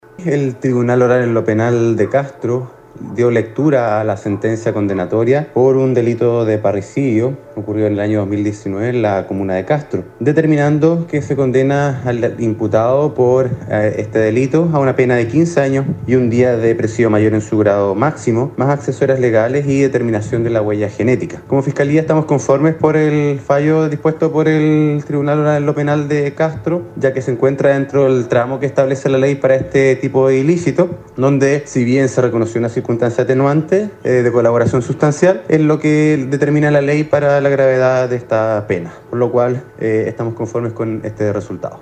El fiscal Luis Barría se refirió al fallo del Tribunal, indicando que se trata de una resolución acorde con lo que perseguía el ministerio Público en este caso, que impactó hace dos años a la comuna de Castro.
Esto fue lo señalado por el persecutor acerca de la pena que deberá cumplir este imputado.